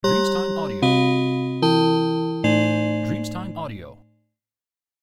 Effetto sonoro di cronaca cronologica